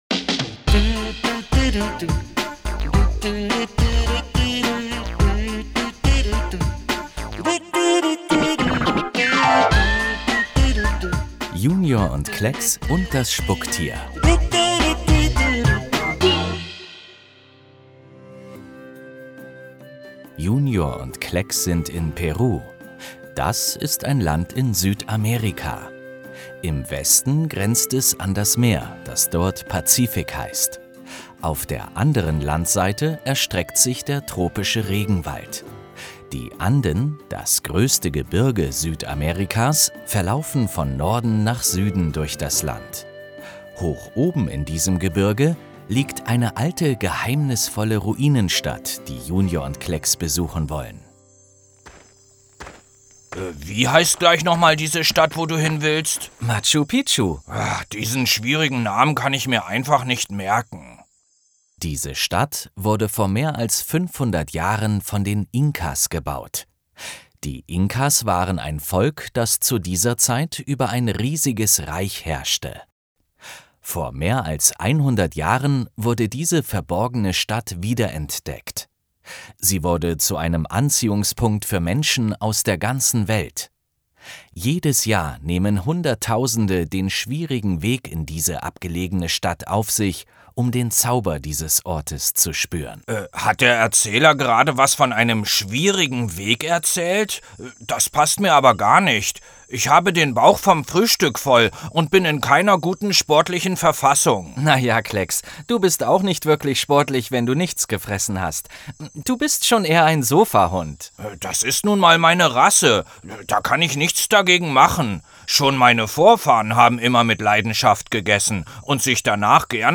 24 09 Hörspiel - JUNIOR Schweiz